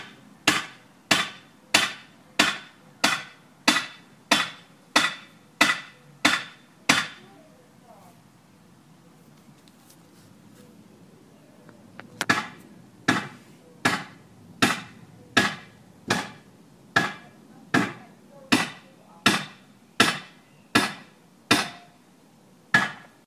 描述：隔壁邻居锤击外面。
Tag: 锤击 花园 建设 施工 外面 建造 建筑